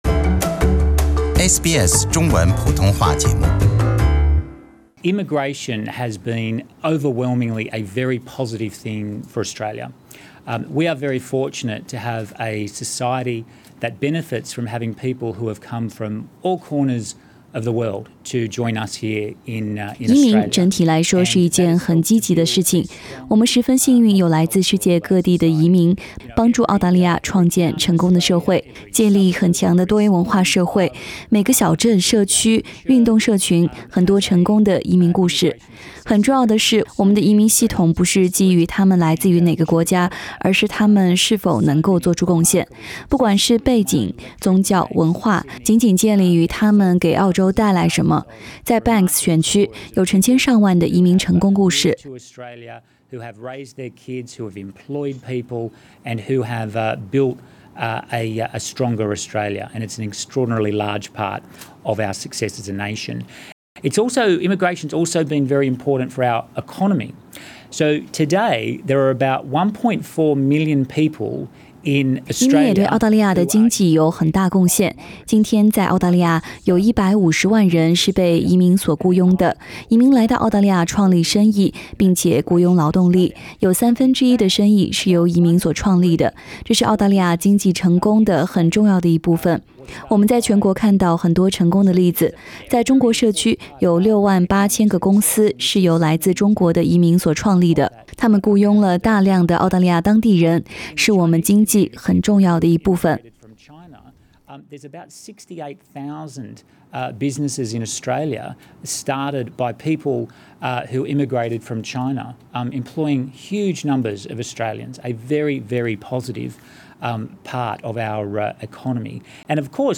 澳大利亞移民，公民及多元文化事務部長David Coleman日前召開多元文化社區記者會。在記者招待會上，他對移民所作出的經濟貢獻表示認可，在澳大利亞有三分之一的生意是移民所創立的，150萬人是由移民所創立的公司所僱傭的。科曼表示，目前他關注的焦點是偏遠地區移民，以及提升臨時籤證類彆持有者的噹地體驗，比如教育旅遊籤證。